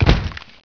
gibheavy.wav